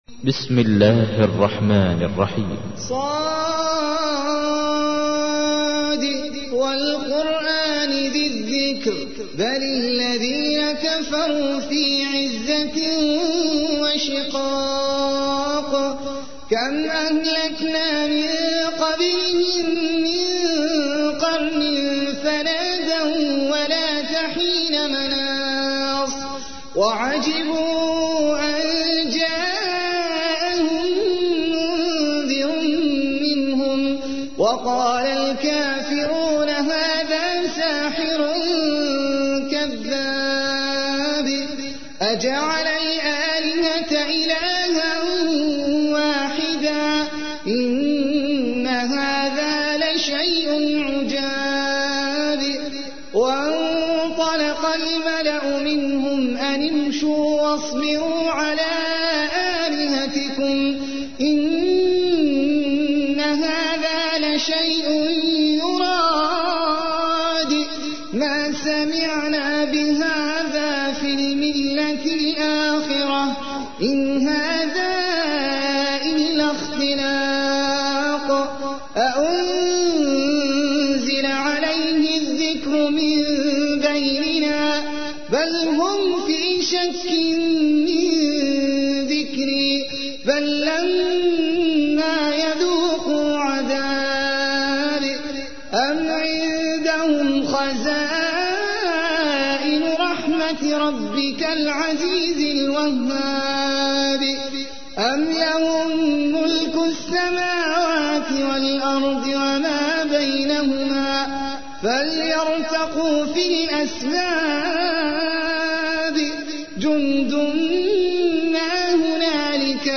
تحميل : 38. سورة ص / القارئ احمد العجمي / القرآن الكريم / موقع يا حسين